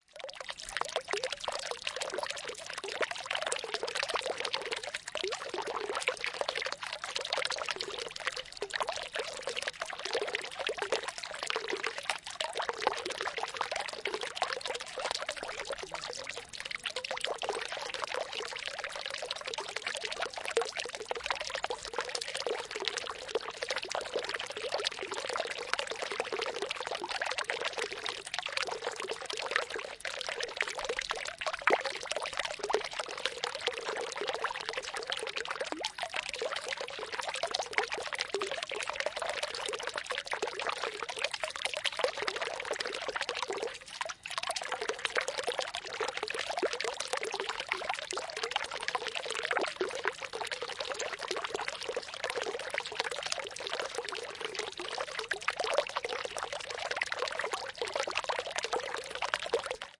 现场记录。水 " 森林河流的漩涡
描述：使用内置麦克风的Zoom H1录制的Wav文件。旋转的水在一条小河在森林里。在提契诺（Tessin），瑞士。
声道立体声